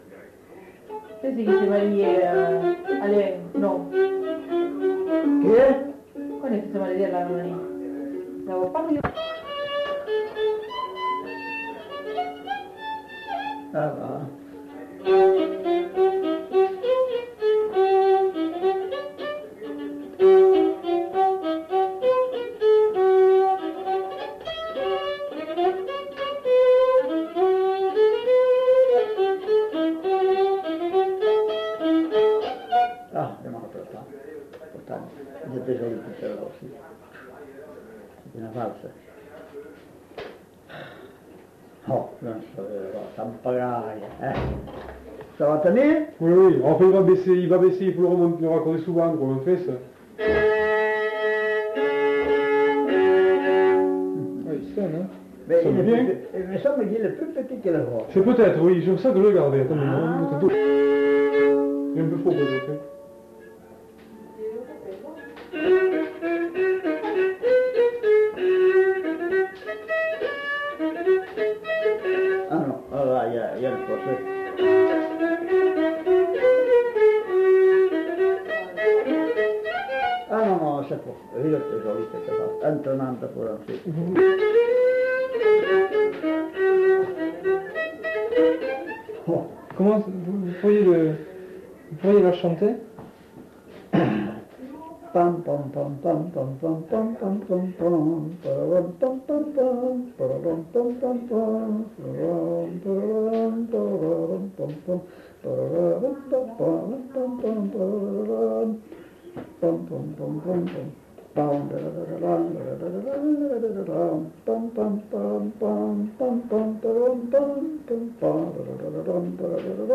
Aire culturelle : Lugues
Lieu : Saint-Michel-de-Castelnau
Genre : morceau instrumental
Instrument de musique : violon
Danse : valse
Notes consultables : L'interprète fredonne l'air.